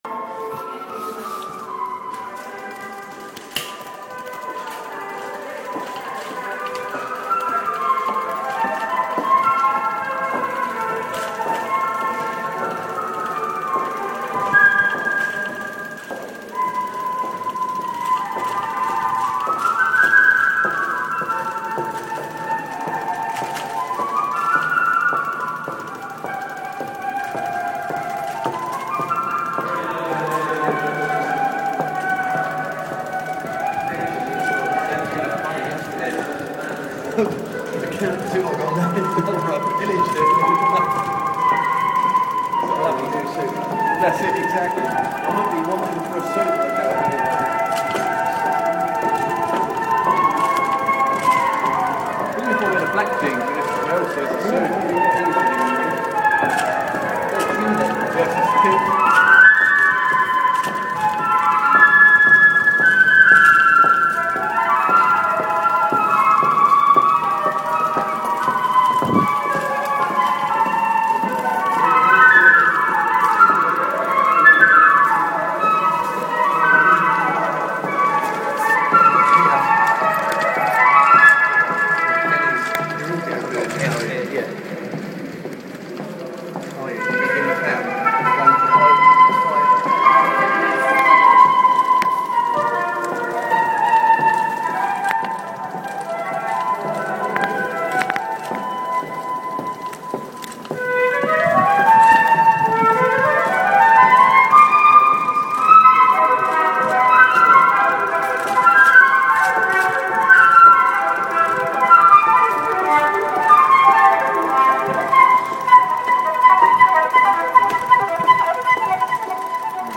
Tunnel Flute Busking